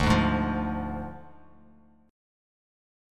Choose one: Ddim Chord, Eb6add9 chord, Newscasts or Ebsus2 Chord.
Ddim Chord